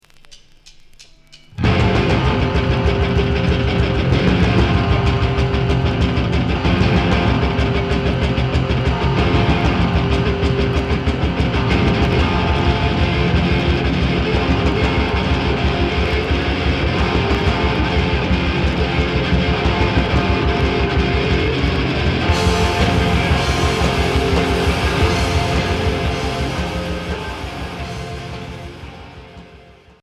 Emo core